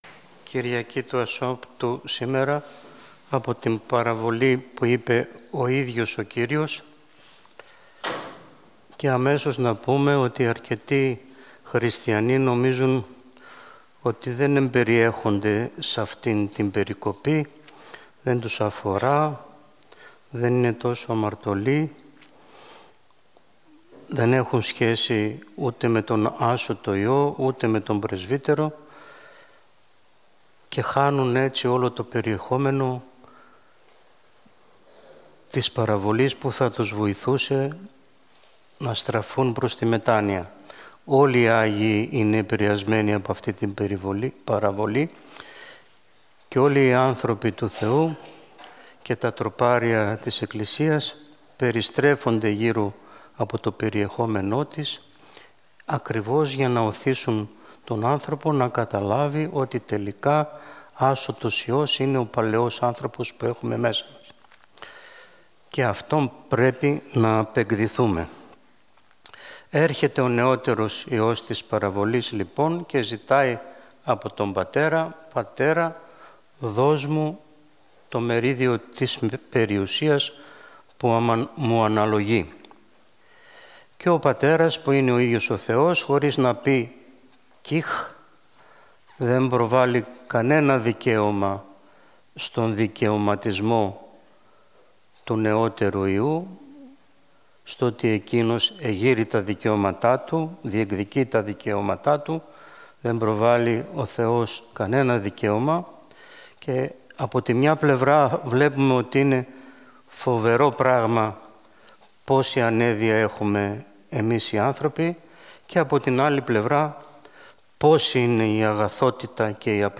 Ὁμιλία